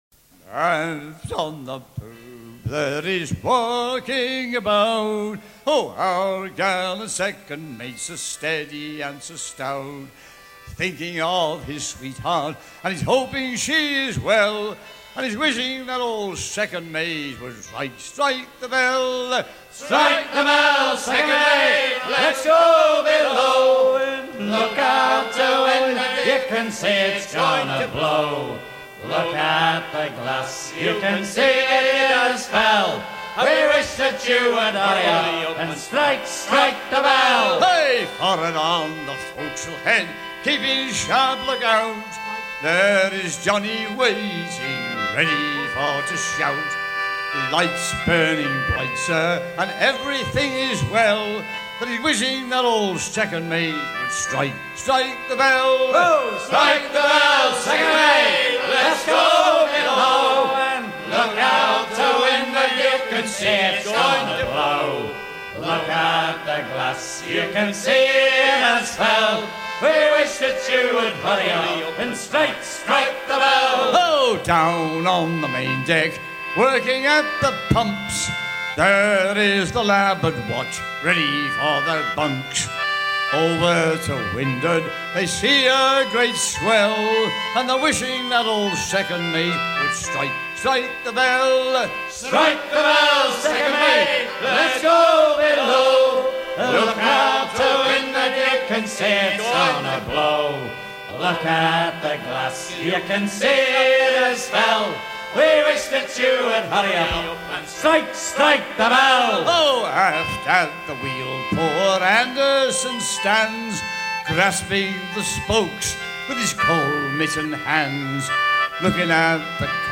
gestuel : à virer au cabestan ; gestuel : à pomper ;
circonstance : maritimes ;
Pièce musicale éditée